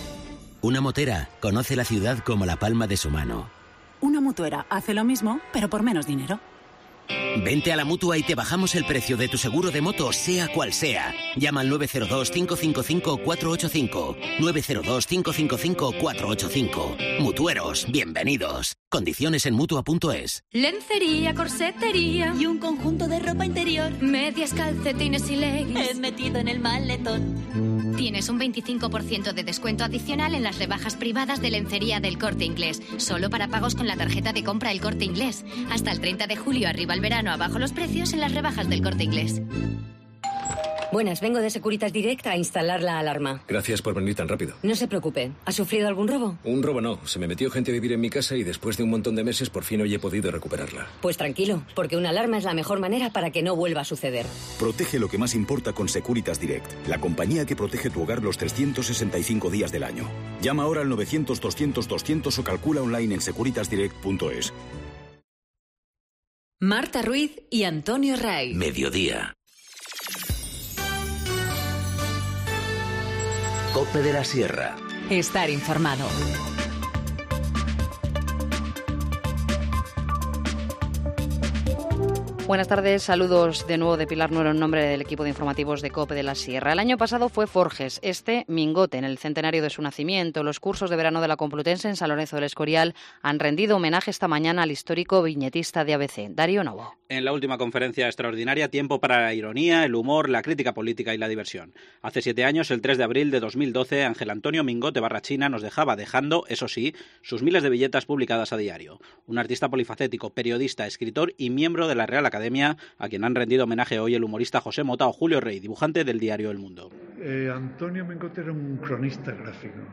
Informativo Mediodía 24 julio 14:50h